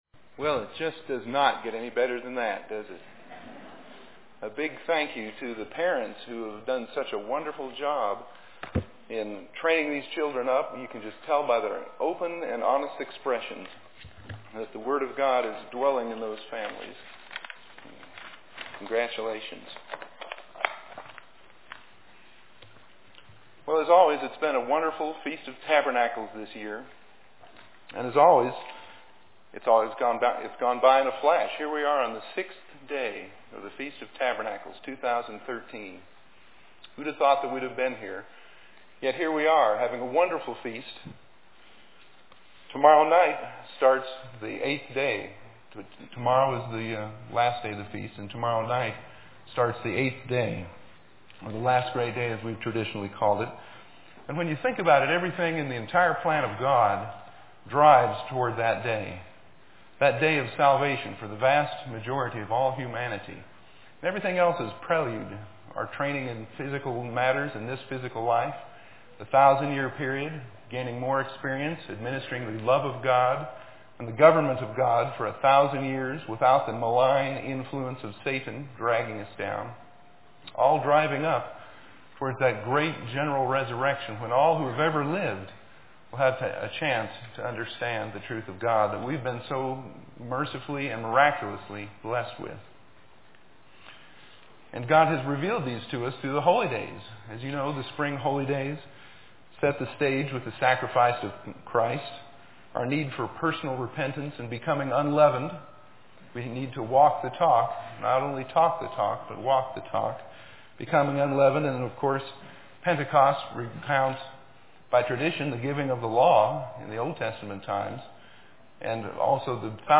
This sermon was given at the Steamboat Springs, Colorado 2013 Feast site.